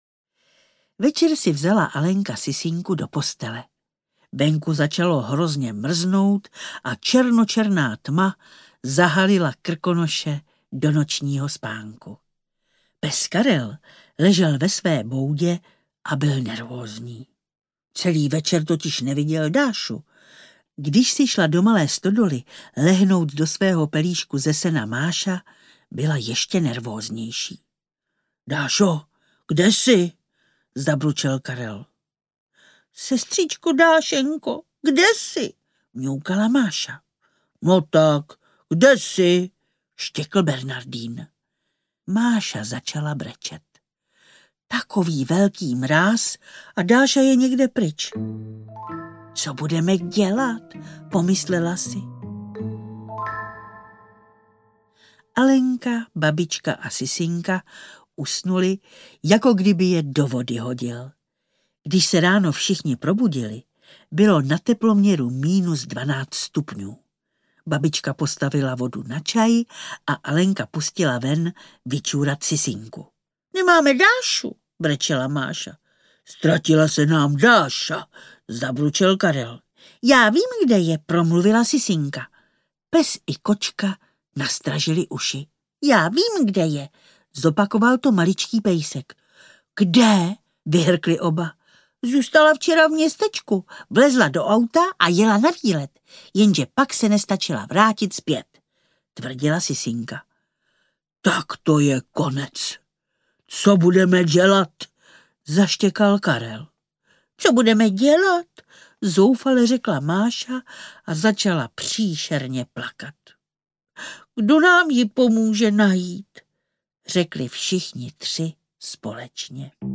Alenka a Krakonoš audiokniha
Ukázka z knihy
Kdoví jak by to s ní dopadlo, kdyby nebylo mocného ochránce hor Krakonoše a ostatních obětavých zvířátek!Půvabné vyprávění v podání Nadi Konvalinkové potěší každého malého posluchače.
• InterpretNaďa Konvalinková